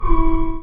alarm_siren_loop_04.wav